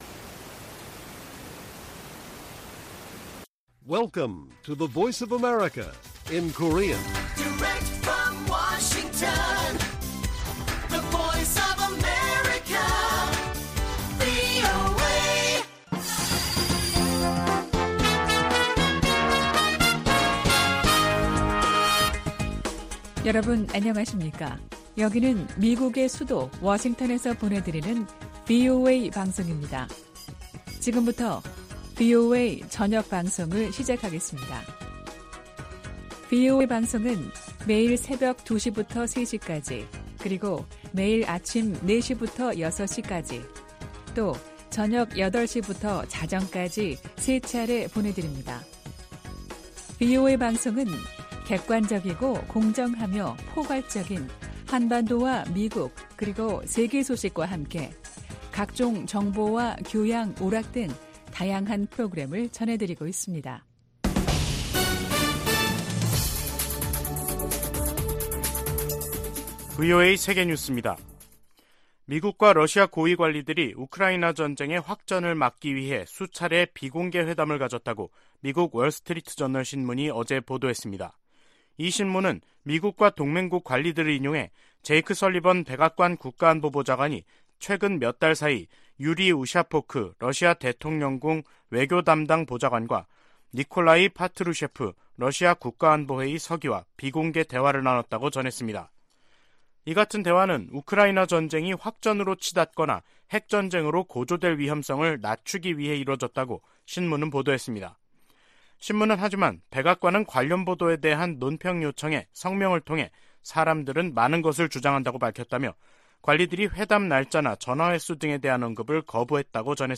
VOA 한국어 간판 뉴스 프로그램 '뉴스 투데이', 2022년 11월 7일 1부 방송입니다. 북한이 미한 연합공중훈련 ‘비질런트 스톰’에 대응한 자신들의 군사작전 내용을 대내외 매체를 통해 비교적 상세히 밝혔습니다. 유엔 안보리가 대륙간탄도미사일(ICBM)을 포함한 북한의 최근 탄도미사일 발사에 대응한 공개회의를 개최하고 북한을 강하게 규탄했습니다.